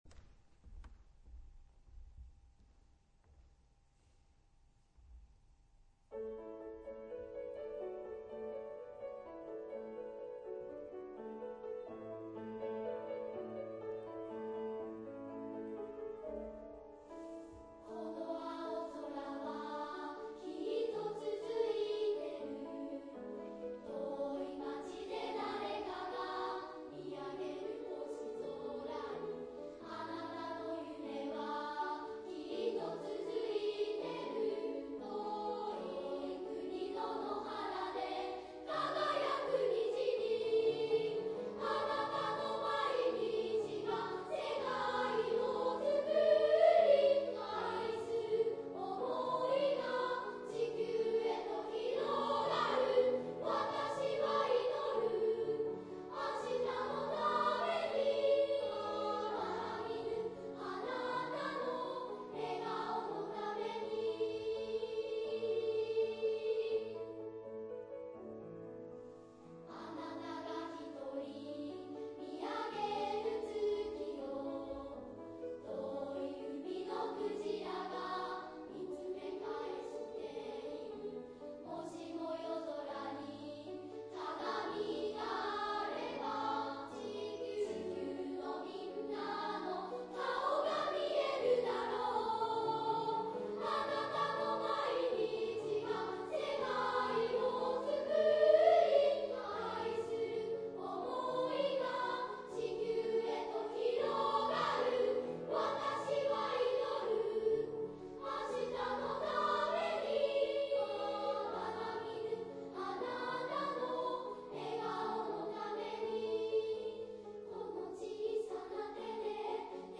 芳賀地区音楽祭（音声ファイルも聴けます！）
本日、真岡市民会館で行われた芳賀地区音楽祭において、合唱部が見事、金賞に輝きました！！１４校の出場校のうち６校が金賞になりました。